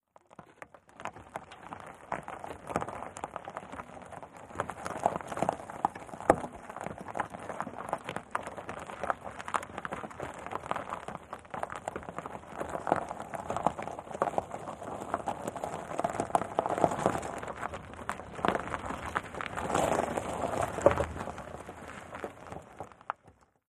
Tires Rolling On Gravel, Cu, Slow.